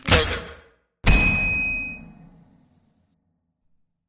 fnf_loss_sfx.mp3